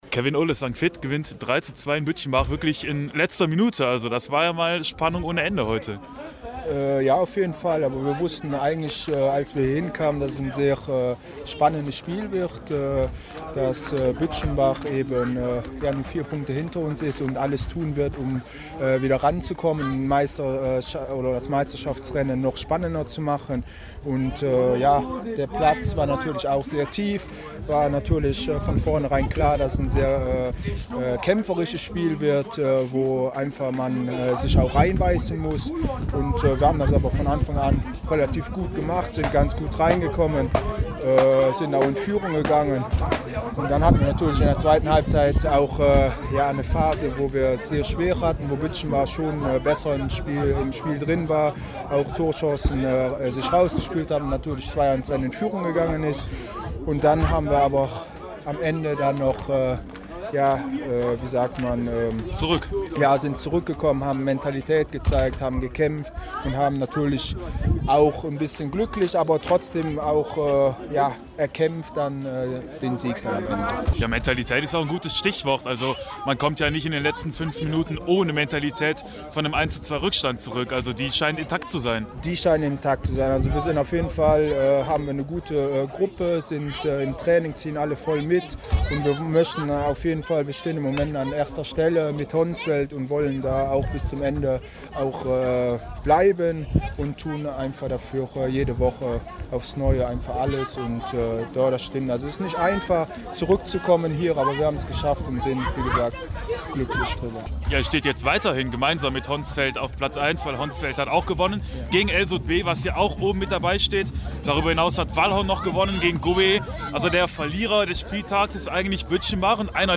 sprach nach dem Spiel